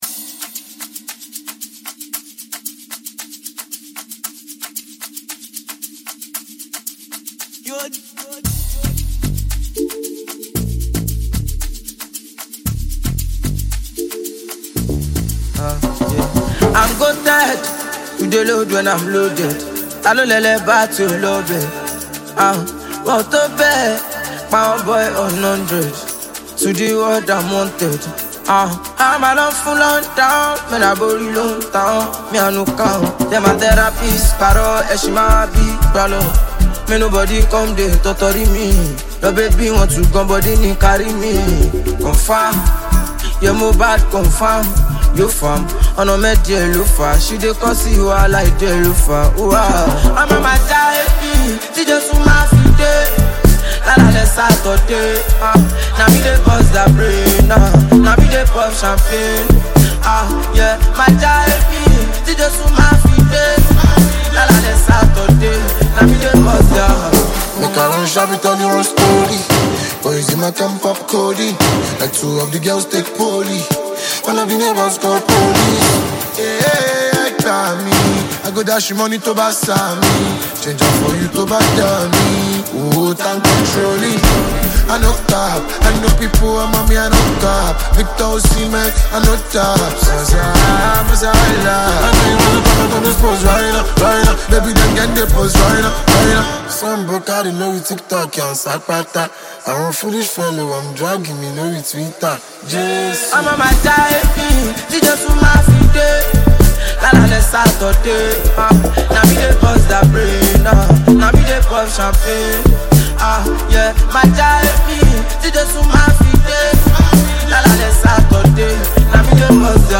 Fast-rising Nigerian singer
a well-known Nigerian Afro-pop singer